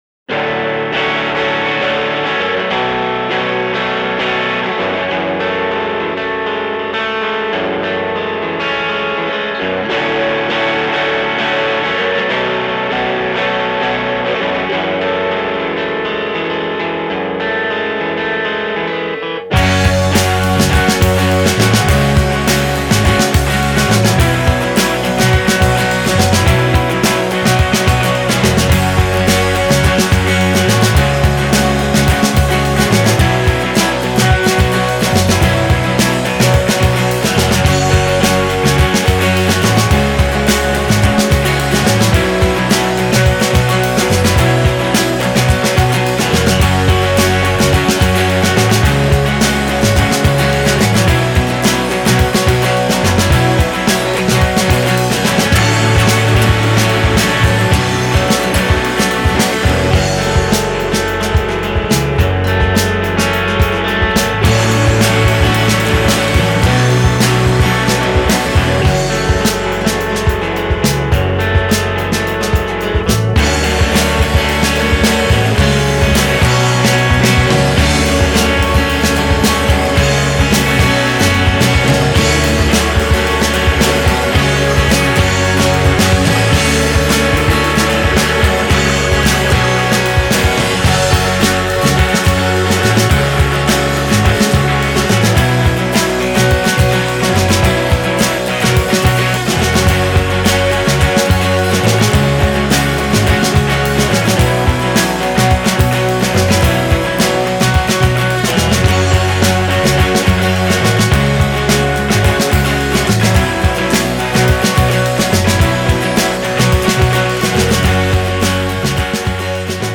guitar
drums, percussion